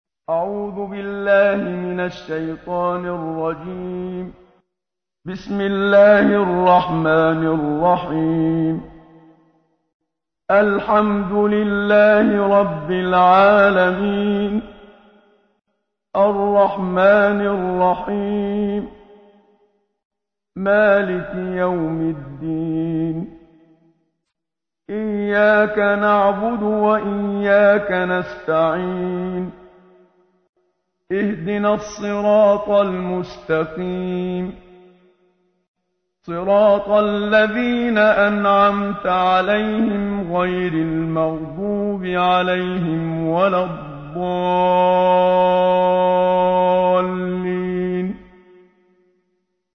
تحميل : 1. سورة الفاتحة / القارئ محمد صديق المنشاوي / القرآن الكريم / موقع يا حسين